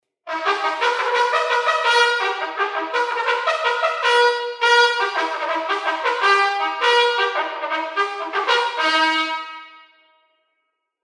Reveille.mp3